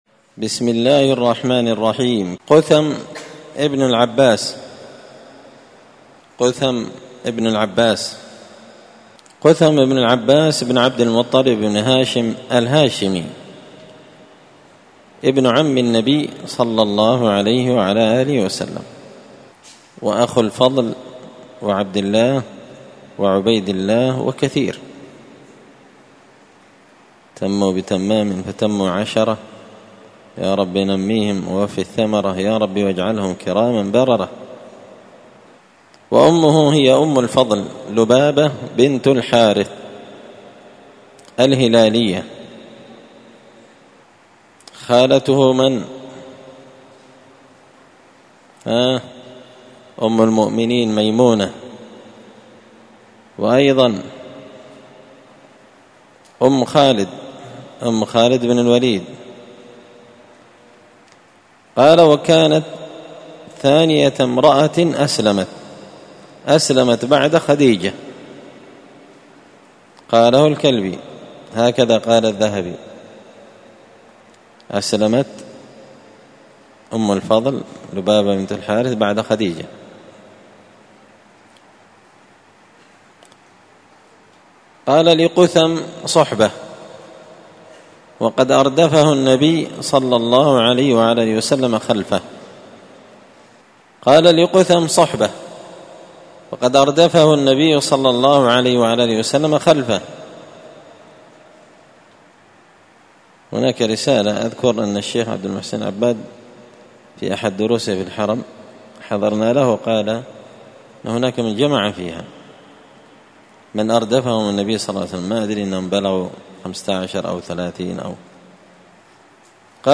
قراءة تراجم من تهذيب سير أعلام النبلاء